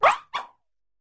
Cri de Pâtachiot dans Pokémon Écarlate et Violet.